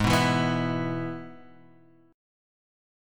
G#m#5 chord